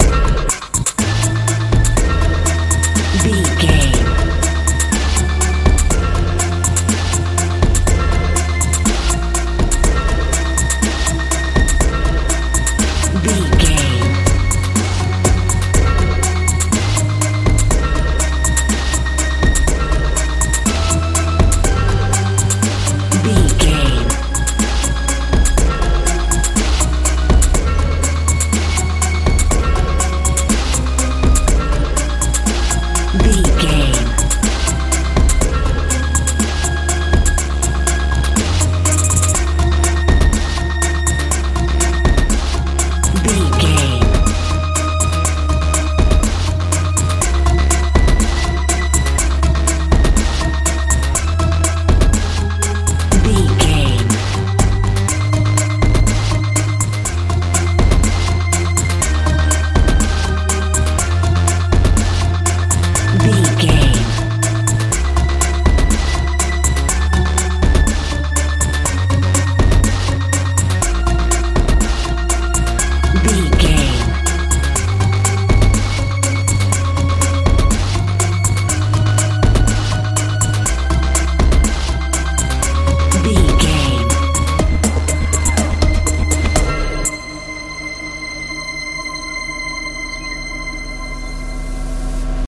modern dance
Aeolian/Minor
futuristic
peaceful
powerful
synthesiser
bass guitar
drums
tension
suspense